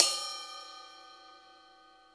• 00's Crash C Key 13.wav
Royality free drum crash tuned to the C note.
00s-crash-c-key-13-pgr.wav